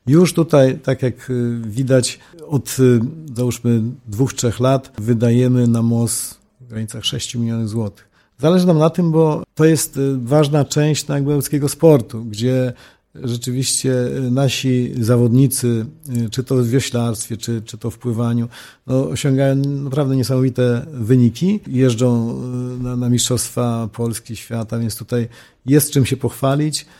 Marek Chojnowski – starosta powiatu ełckiego.